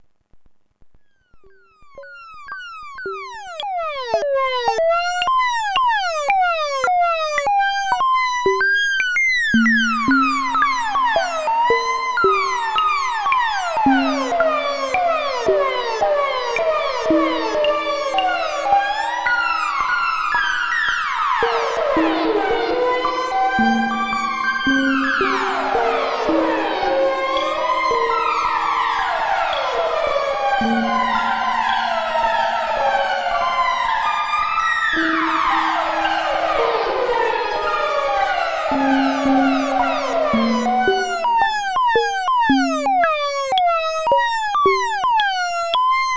And here is another pair of sound clips comparing the 190 with 2 different sized reverb tanks, using some 200 series modules as well:
Sound Sample with A4B3C1A "long" tanks.